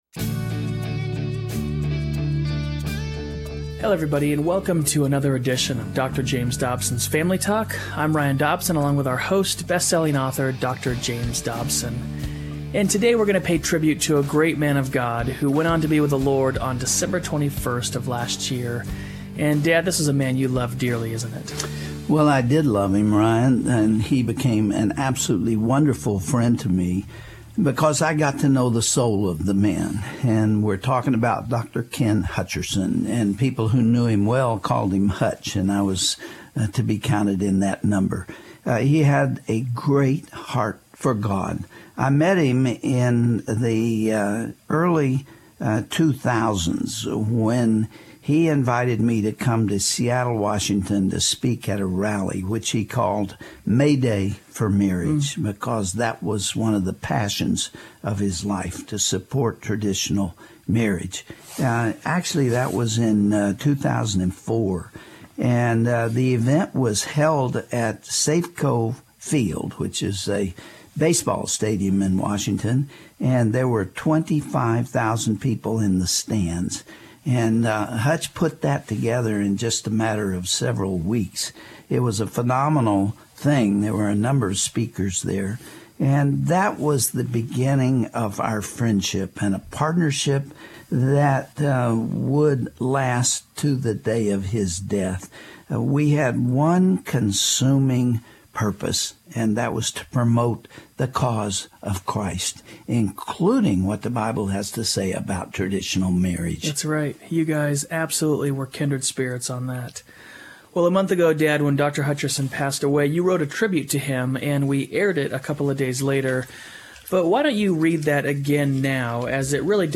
Dr. Dobson interviews